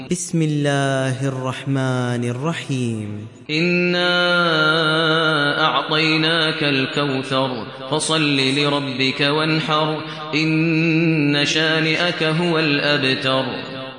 Surah Al Kawthar mp3 Download Maher Al Muaiqly (Riwayat Hafs)